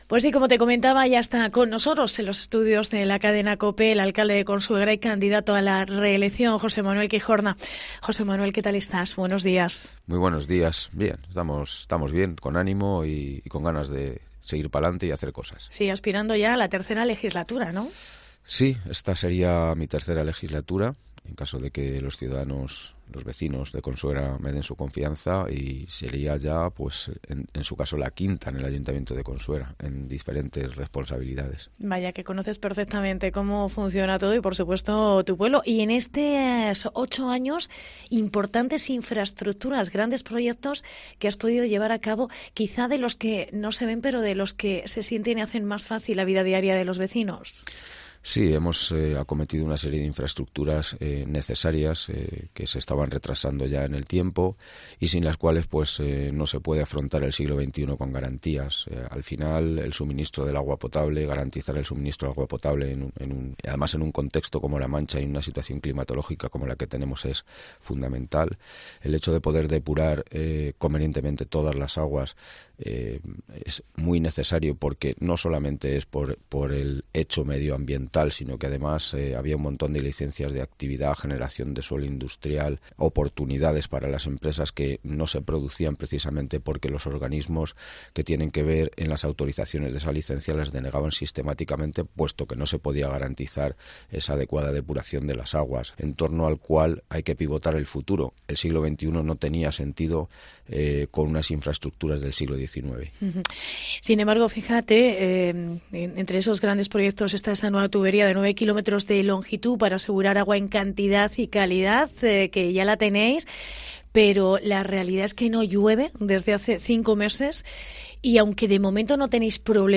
Hoy nos visita en "Herrera en COPE Toledo" el alcalde y candidato a la reelección José Manuel Quijorna.